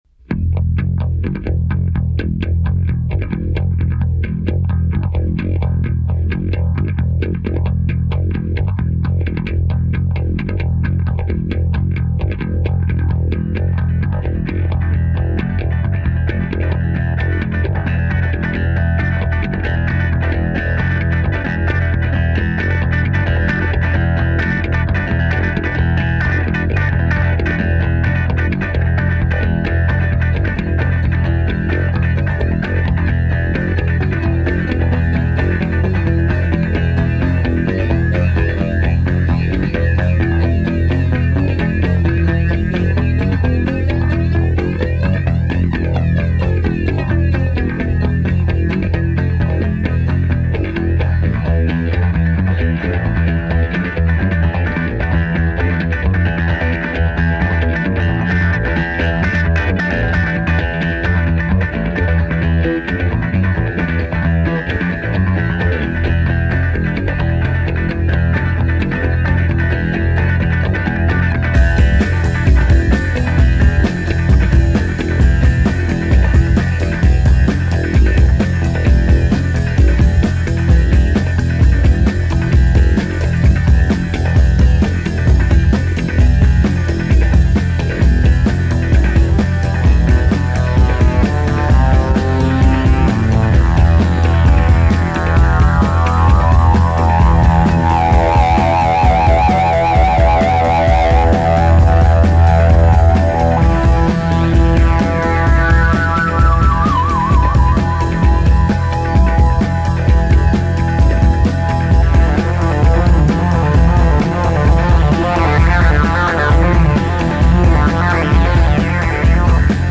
theme music